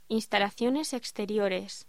Locución: Instalaciones exteriores
voz